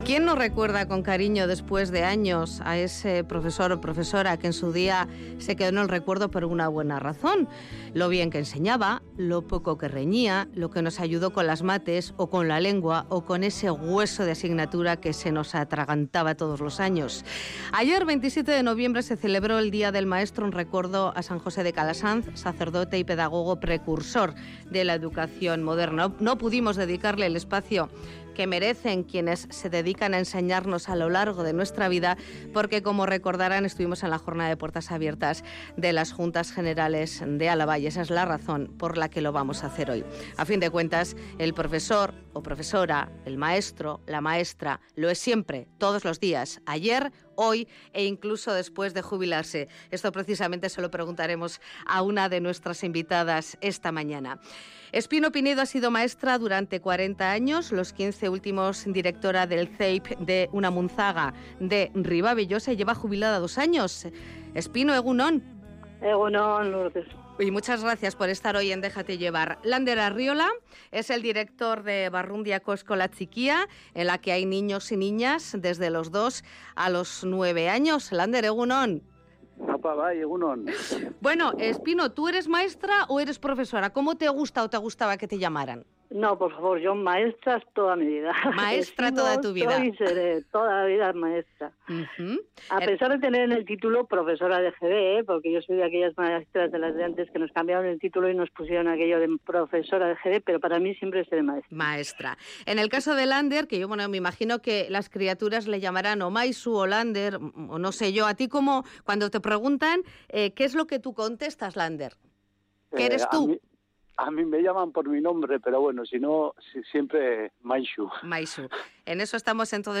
Audio: En el Día del Maestro charlamos con quienes enseñan en la zona rural de nuestro territorio